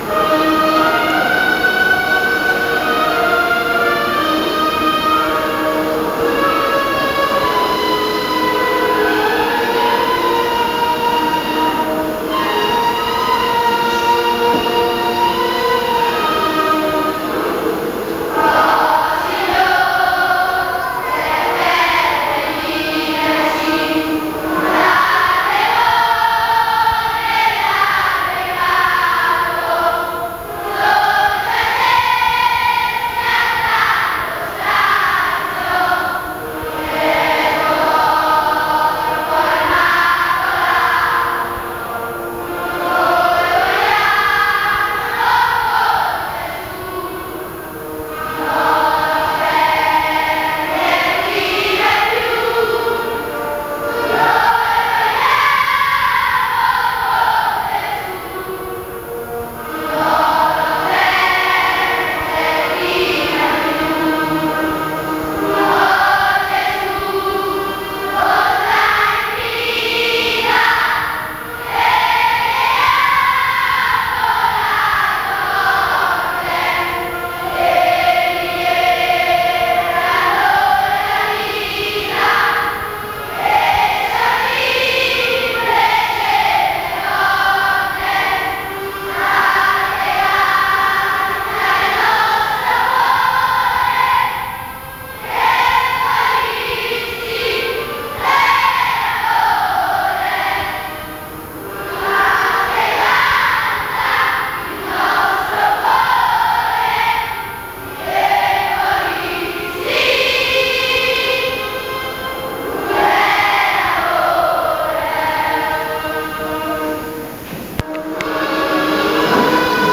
Ovviamente le registrazioni sono a livello amatoriale, per lo più registrate "live" sulle strade, se qualcuno fosse in possesso di registrazioni migliori può, se lo desidera, inviarcele in modo che esse siano messe a disposizione di tutti.